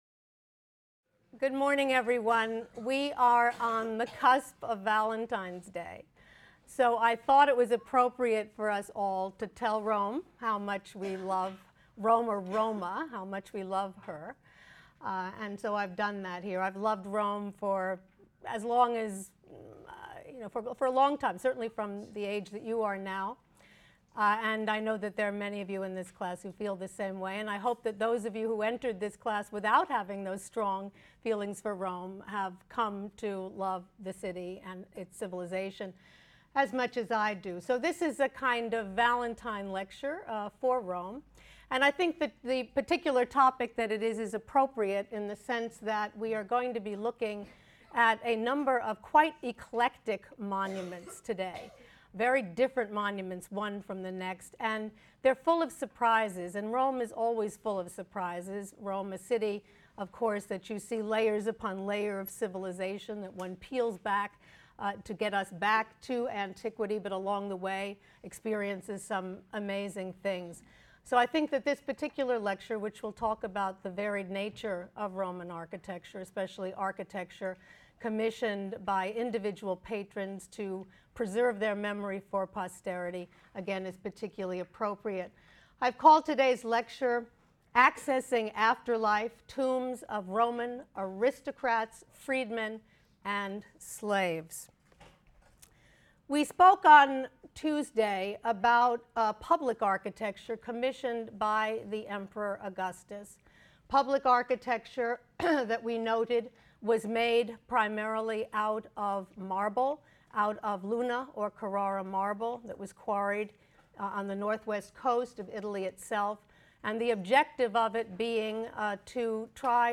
HSAR 252 - Lecture 10 - Accessing Afterlife: Tombs of Roman Aristocrats, Freedmen, and Slaves | Open Yale Courses